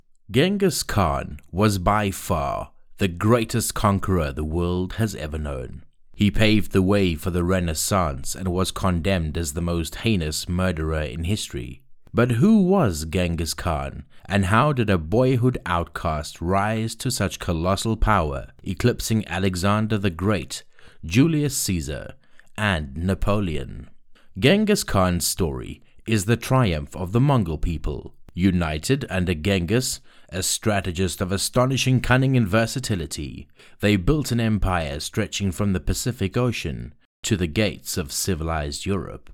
My voice is clear, engaging, versatile, and perfect for:
Fast turnaround. Studio-quality sound. No fuss, just clean, compelling audio tailored to your vision.
Genghis-Khan-audiobook.mp3